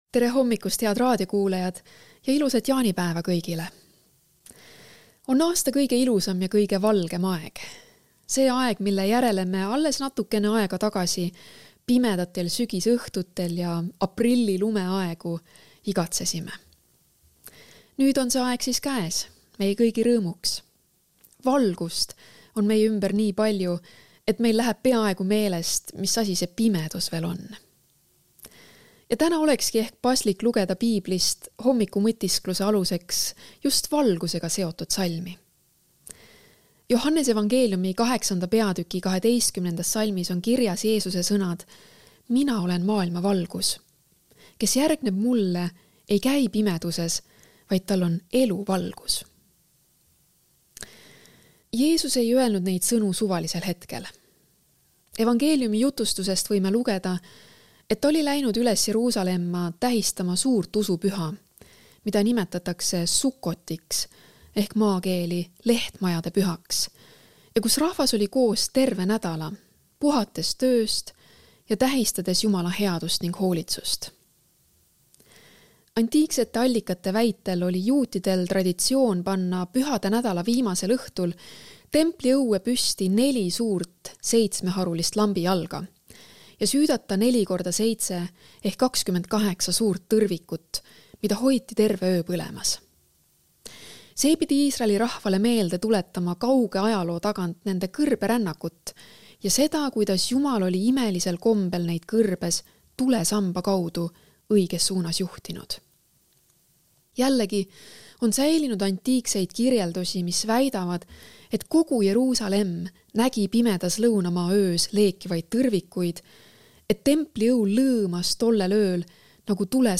hommikupalvus ERR-is 24.06.2024